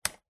Звуки переключателя, выключателя
Щелчок выключателя потолочного вентилятора 2